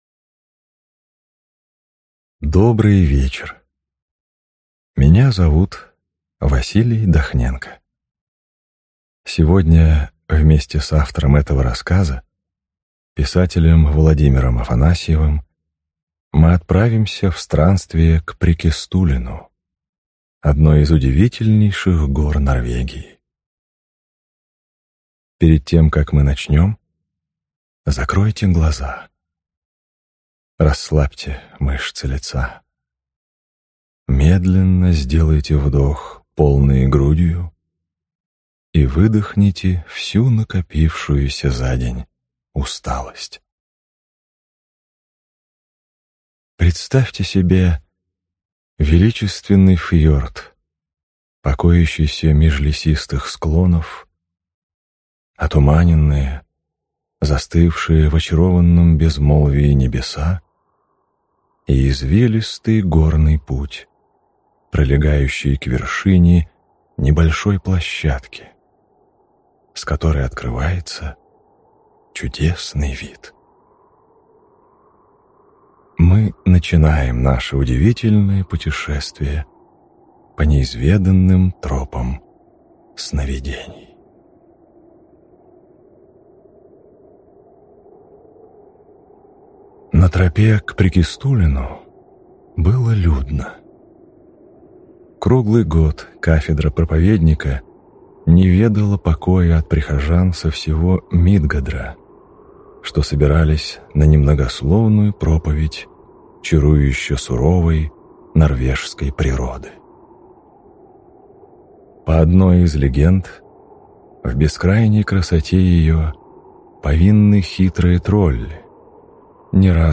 Аудиокнига Прейкестолен | Библиотека аудиокниг